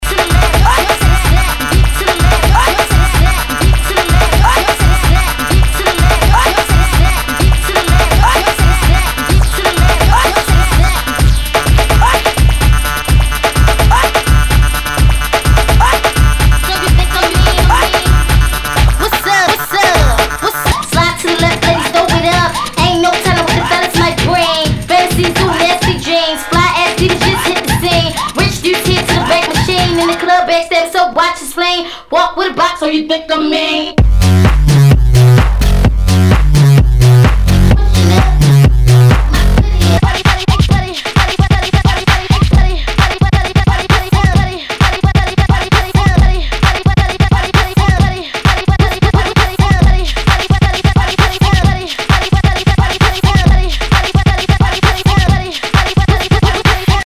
HOUSE/TECHNO/ELECTRO
ナイス！エレクトロ・ハウス！